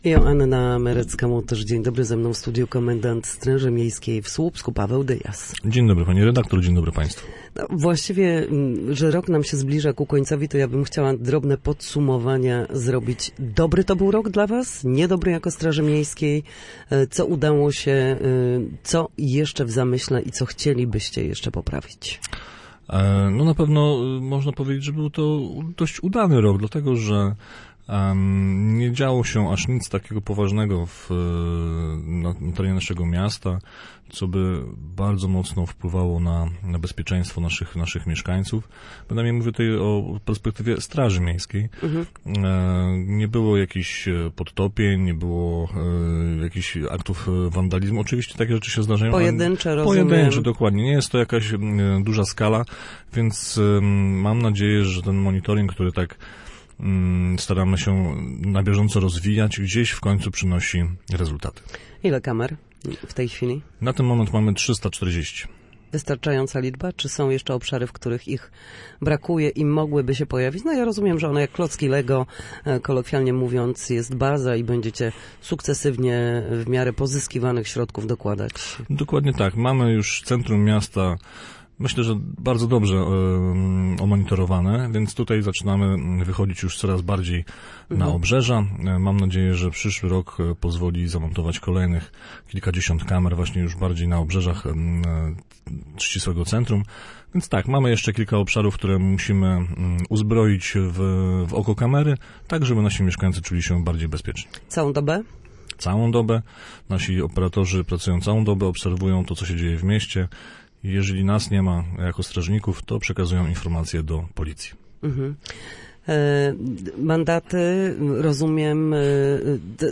Gościem Studia Słupsk był Paweł Dyjas, komendant Straży Miejskiej w Słupsku. Na naszej antenie mówił o wsparciu osób w kryzysie bezdomności oraz mandatach, których w tym sezonie grzewczym jak dotąd nie wystawiła straż miejska.